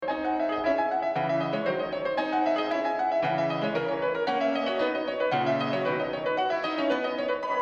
I was recording one concert of classical music from two different spots so my sound is not the same on these two recordings.
View attachment sonate_1.mp3
The definition of the first reverberant clip is less good, of course as the mics are simply too far away from the source, but you should be able to replicate it reasonably close.